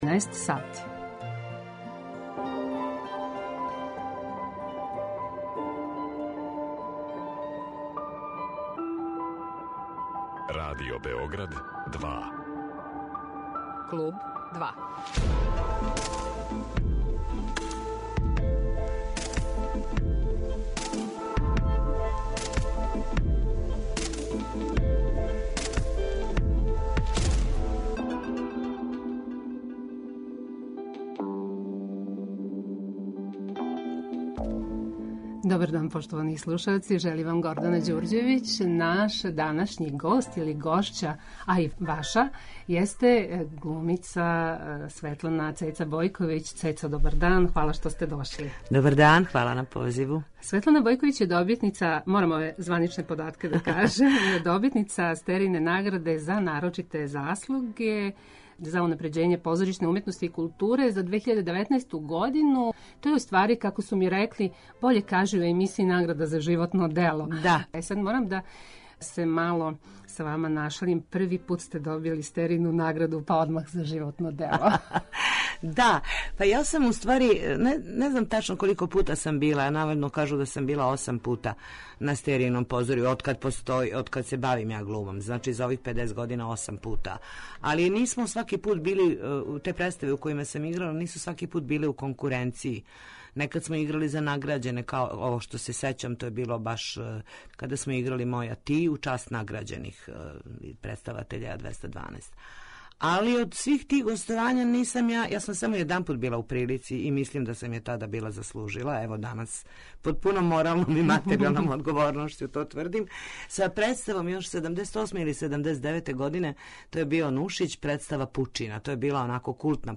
Гост је глумица Светлана Бојковић